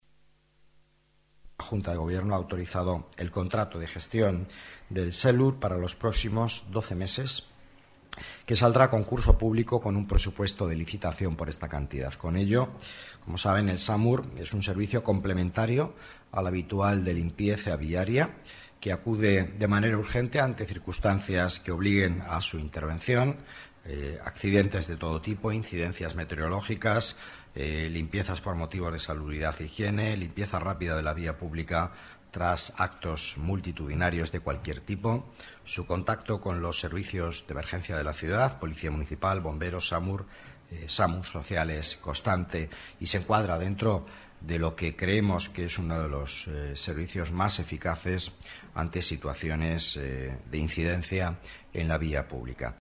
Nueva ventana:Declaraciones del vicealcalde, Miguel Ángel Villanueva